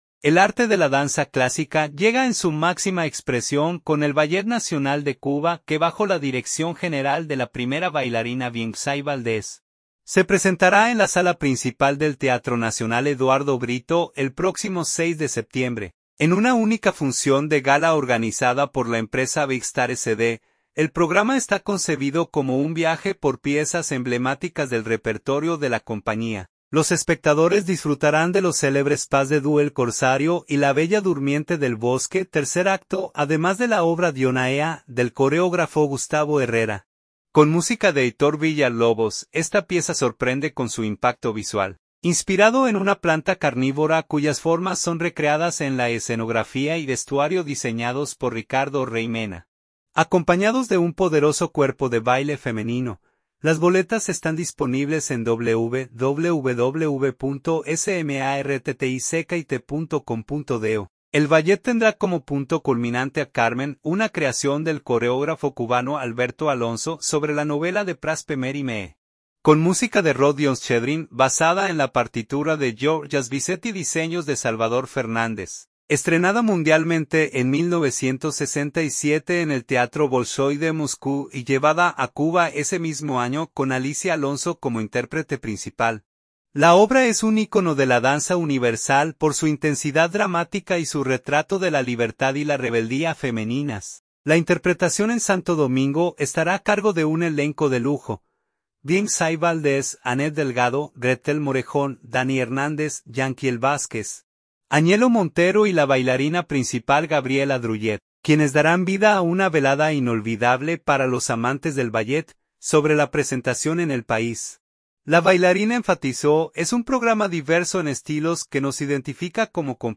— Entrevista